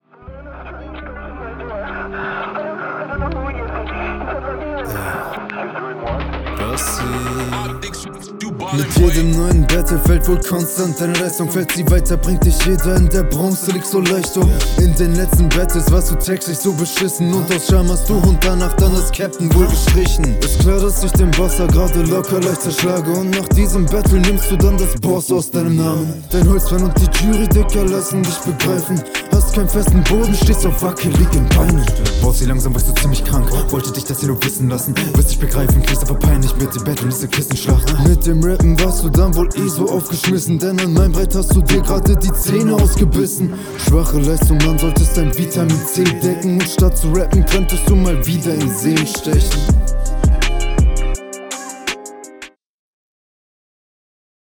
Schnellen flowpasagen sind cool.
Das ist wieder viel zu tiefgedrückt. Auch hier gehen die Betonungen also nicht auf.